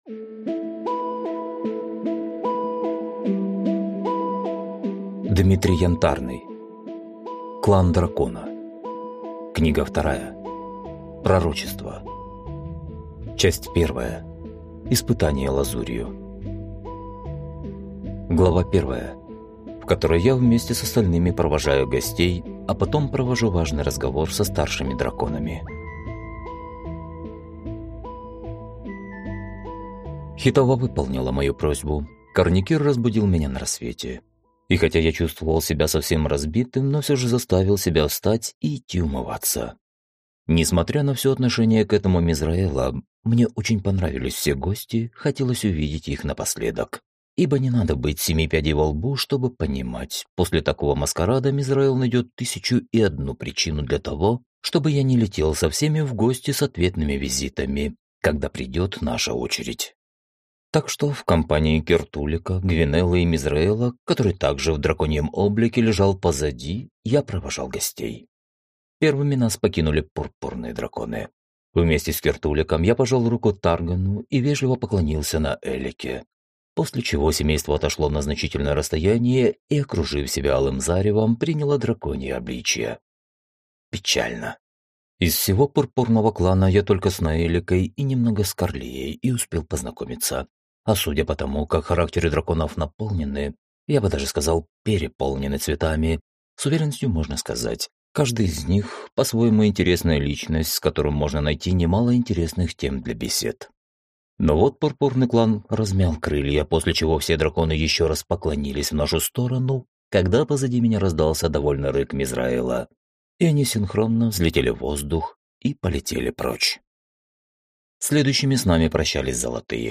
Аудиокнига Клан дракона. Книга 2. Пророчество | Библиотека аудиокниг